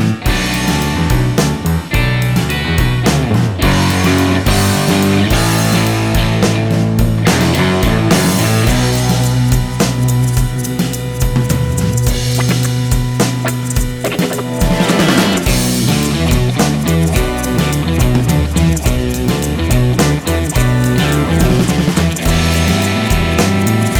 Minus Guitar Solo Indie